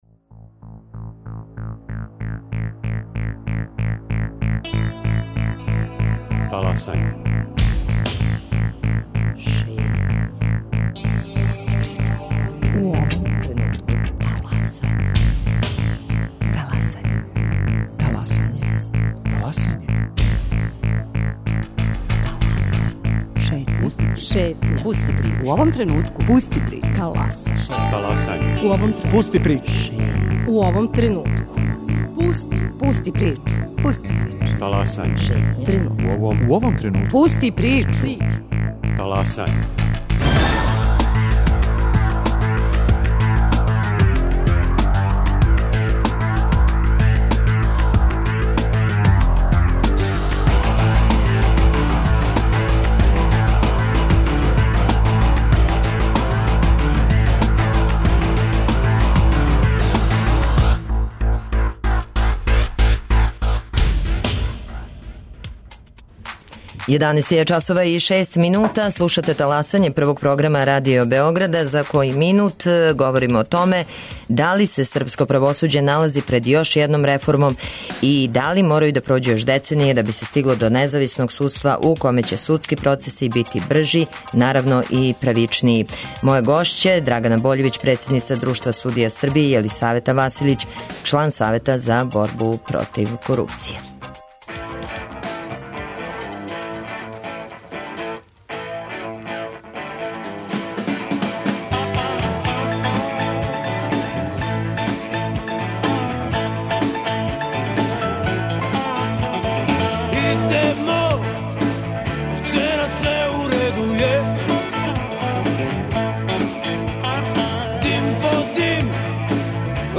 Snimak emisije